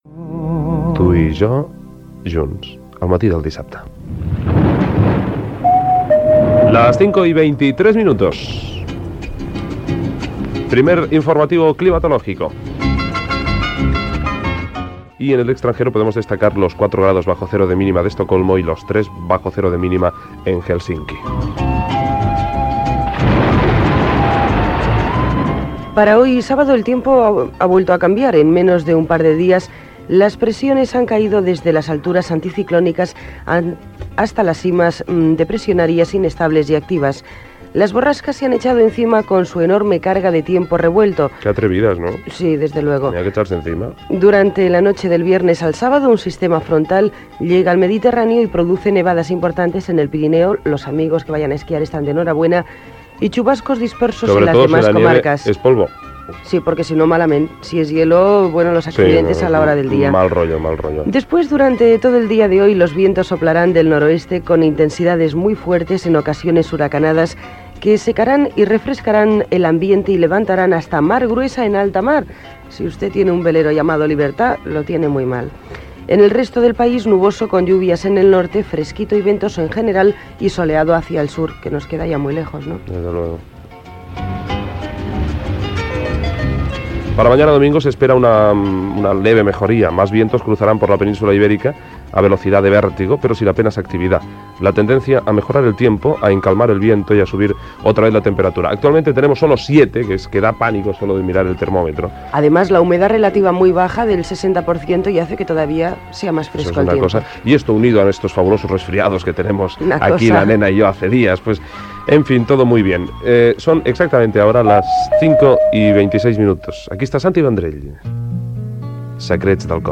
Hora, temperatures a Europa, estat del temps i previsió meteorològica, hora i tema musical.
Entreteniment